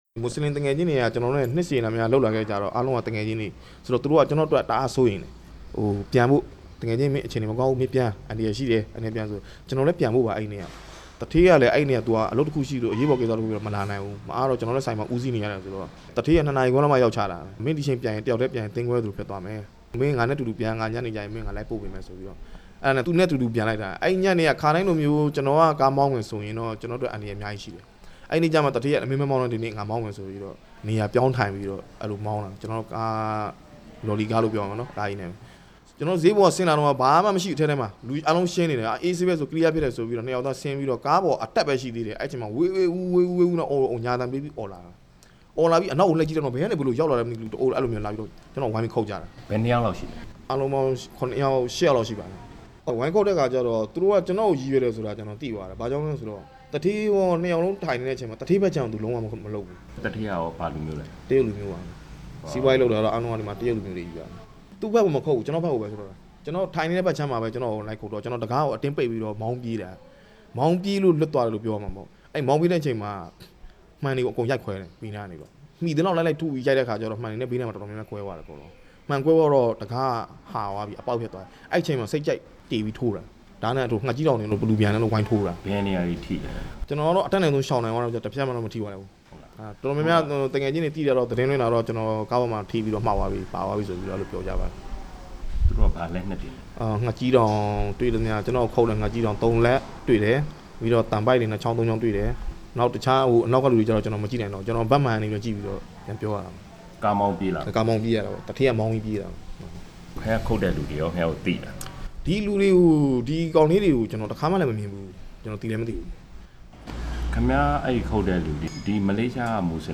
မလေးရှားနိုင်ငံမှာ ရန်ပြုအတိုက်ခံရတဲ့ မြန်မာတစ်ဦးနဲ့ မေးမြန်းခန်း